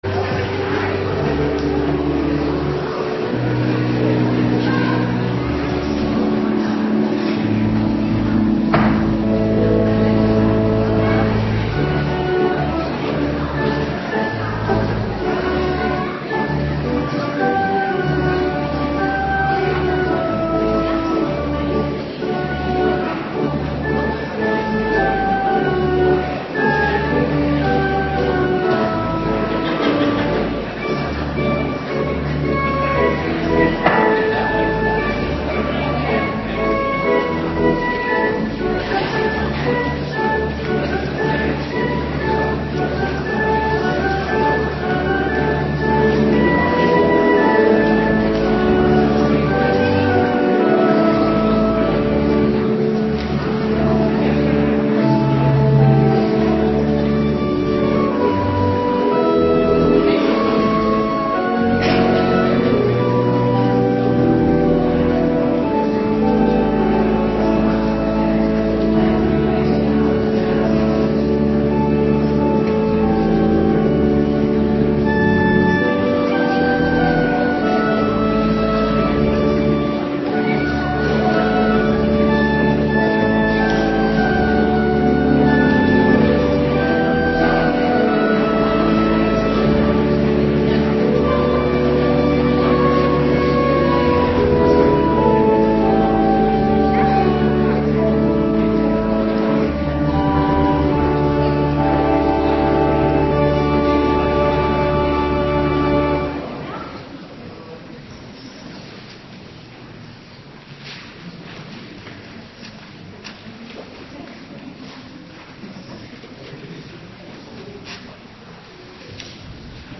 Avonddienst 24 december 2025
Kerstzangdienst m.m.v. kinderprojectkoor Ismaël en Interkerkelijk Gemengd Koor Watergraafsmeer.